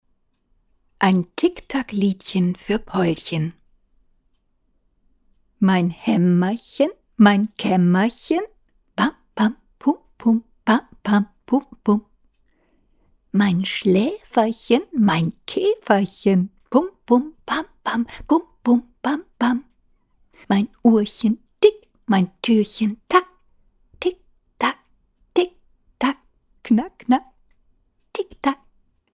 In einem anderen Forum wurden mir die Filter RX7 von Izotope empfohlen. Ich bin sehr angetan, weil die Vocalbearbeitung dadurch weitgehend automatisiert werden kann.
Ein Beispiel eines Gedichts anbei.